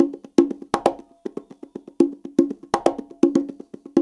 CONGA BEAT37.wav